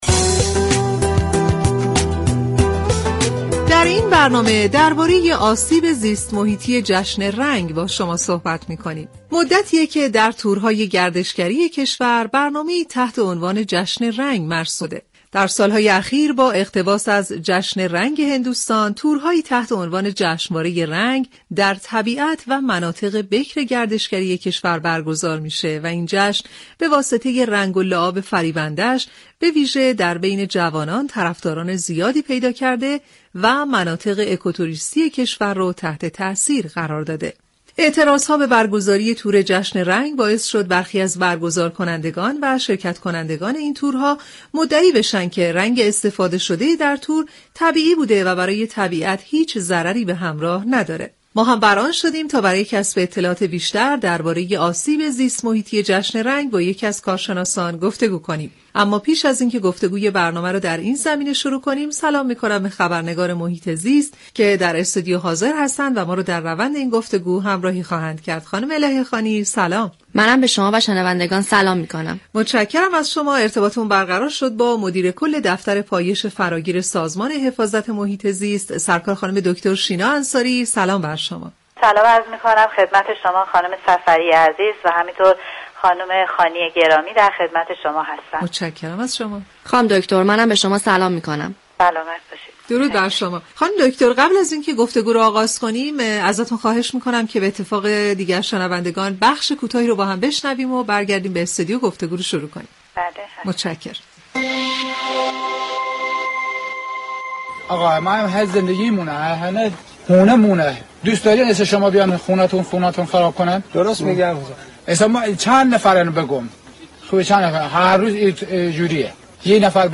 دكتر شینا انصاری مدیر كل دفتر فراگیر سازمان حفاظت محیط زیست در برنامه سیاره آبی رادیو ایران